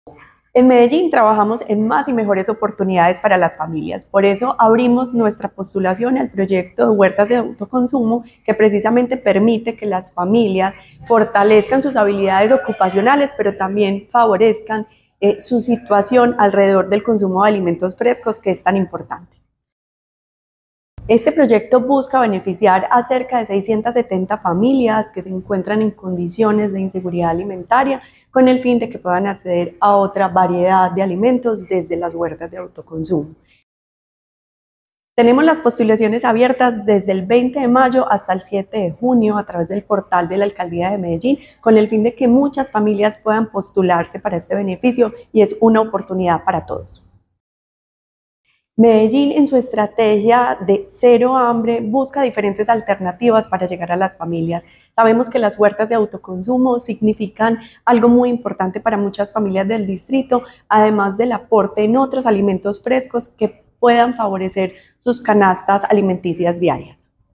Palabras de Sandra Sánchez, secretaria de Inclusión Social y Familia